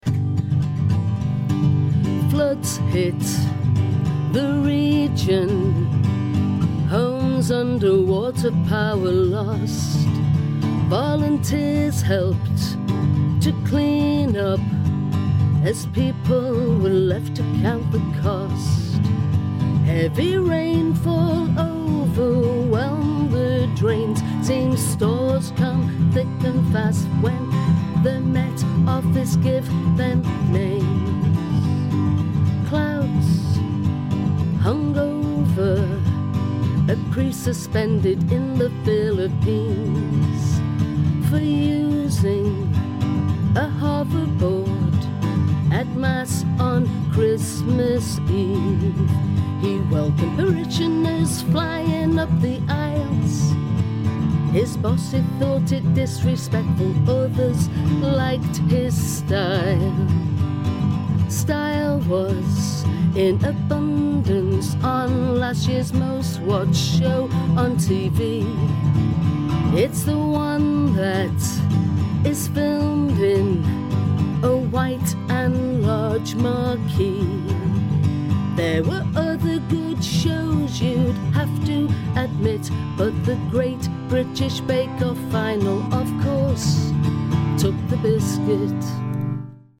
Get the week's news in song